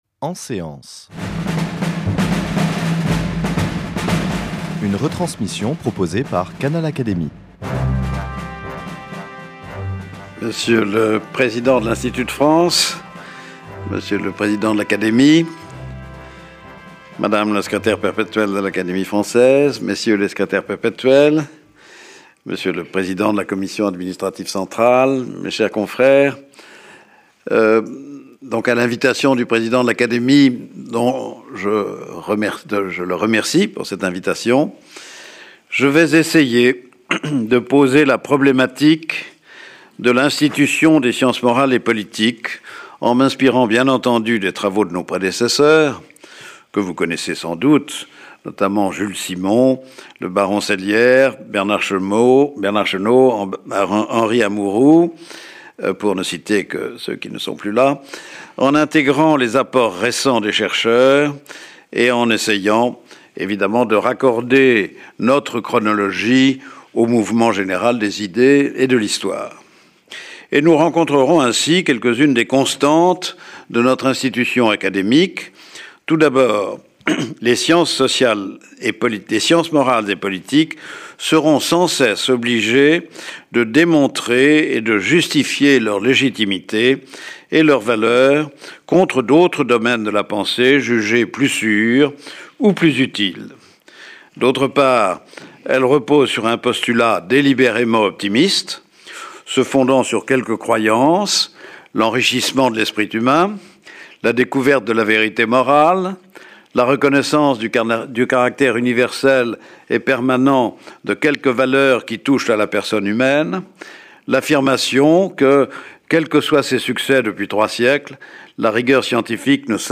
Cette séance a été enregistrée le 6 janvier 2014. La séance est suivie des débats, contenu exclusif réservé au membres du Club Canal Académie.